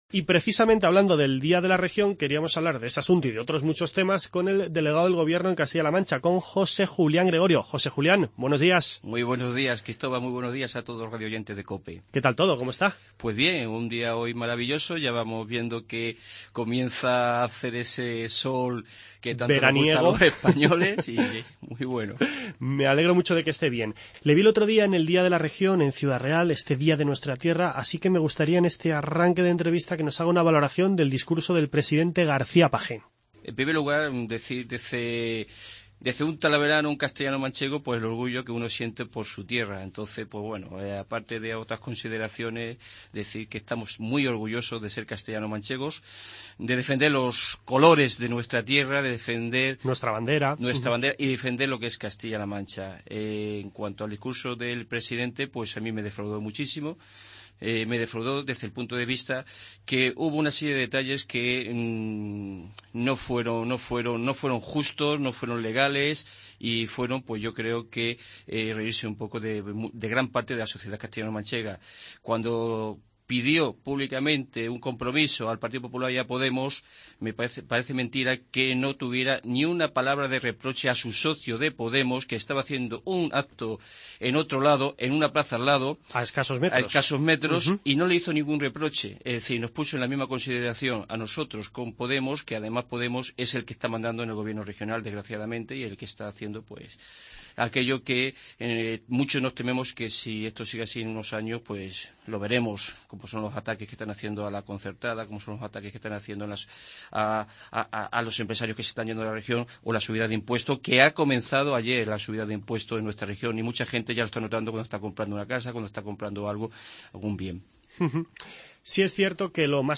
Charlamos con el delegado del Gobierno en Castilla-La Mancha, José Julián Gregorio.